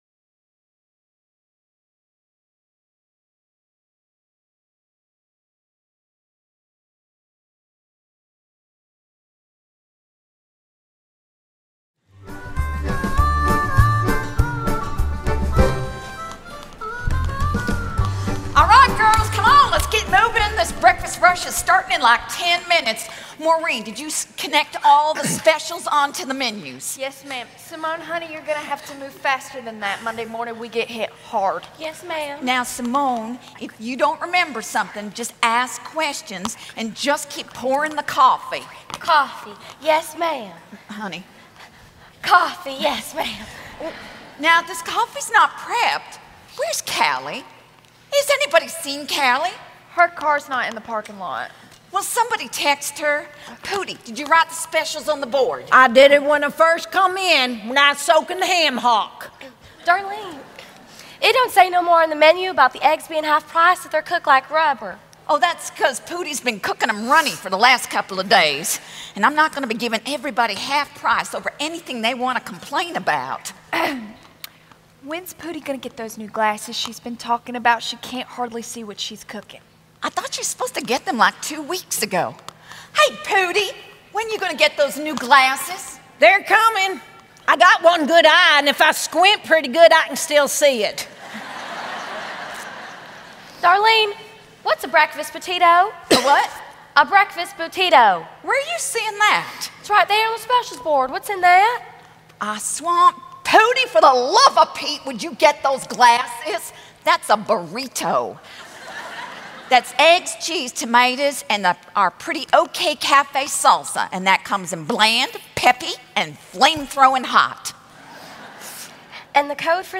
Message 8 (Drama): Pretty Okay Café
Revive '17 Adorned Conference - DVD Set